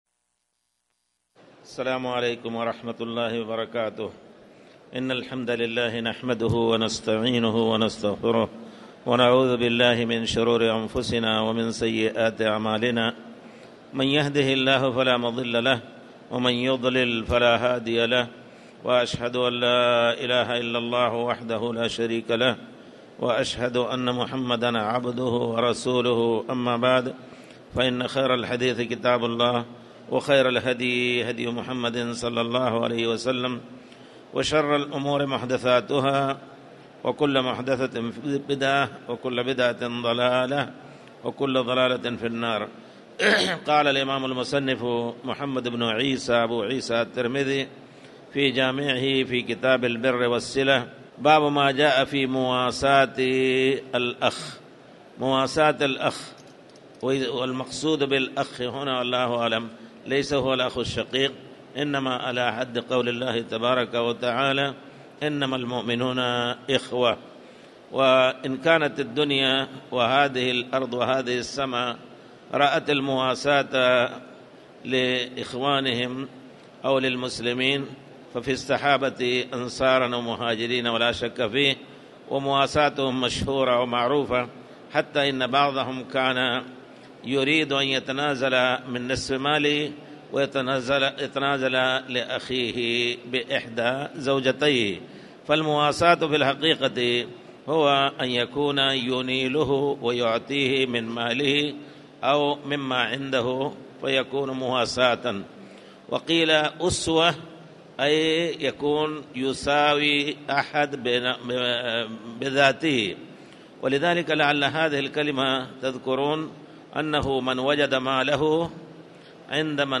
تاريخ النشر ١١ ذو القعدة ١٤٣٨ هـ المكان: المسجد الحرام الشيخ